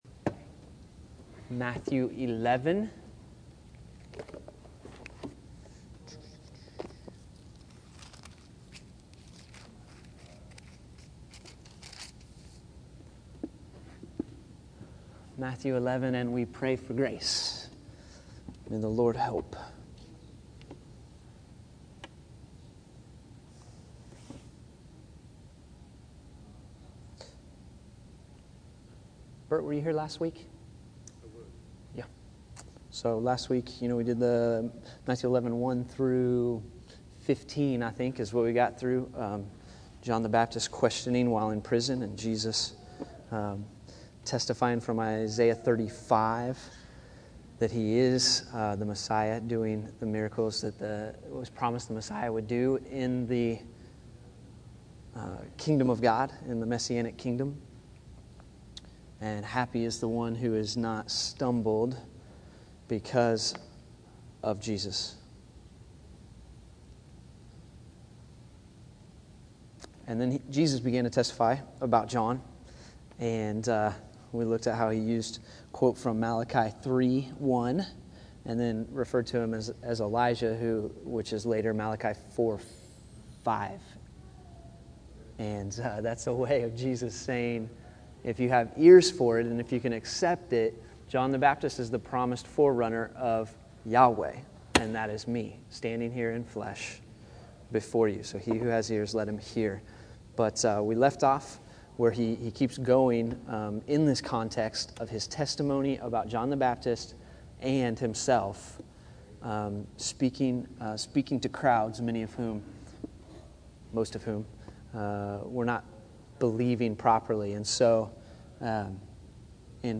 Matthew 11:16-19 July 28, 2013 Category: Sunday School | Location: El Dorado Back to the Resource Library The Pharisees conceived of holiness as separation, but Jesus, as merciful participation. They didn't want to receive John the Baptist or Jesus, but God is vindicated through His Wisdom incarnate.